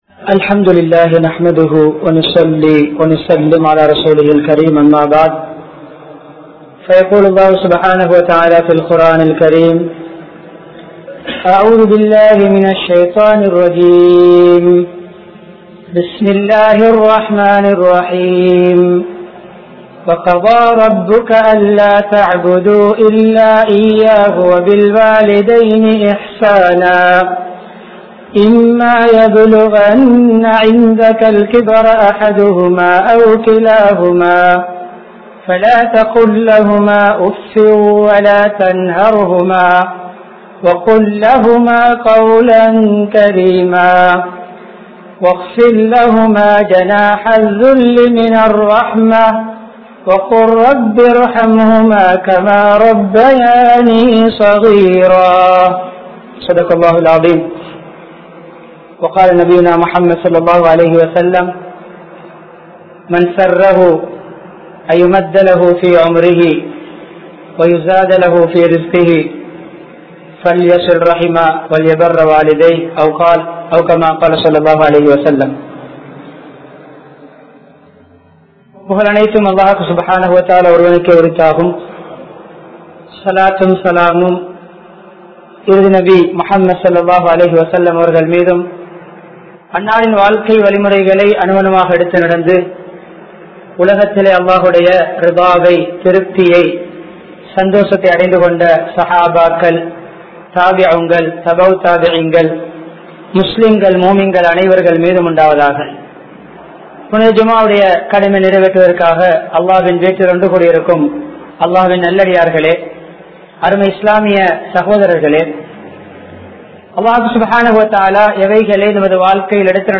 Petrorai Othukka Vendaam! (பெற்றோரை ஒதுக்க வேண்டாம்!) | Audio Bayans | All Ceylon Muslim Youth Community | Addalaichenai
Jumuah Masjith